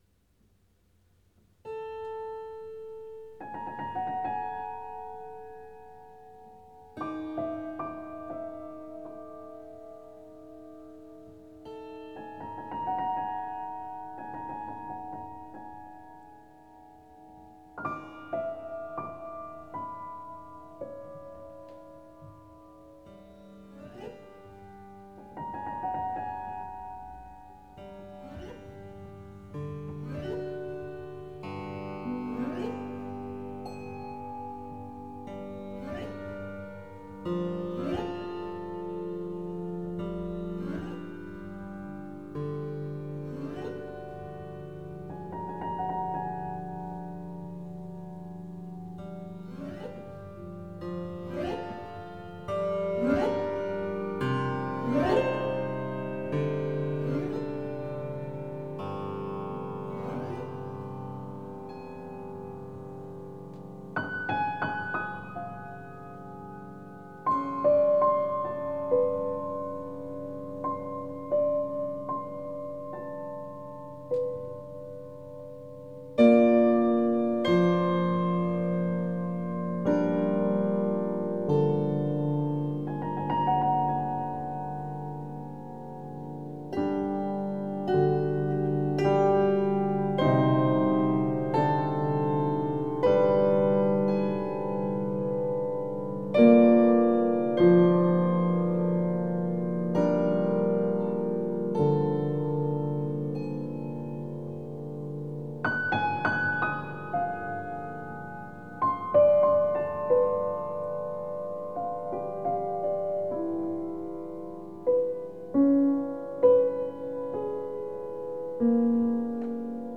is contrasted with atonal or at least dissonant harmonies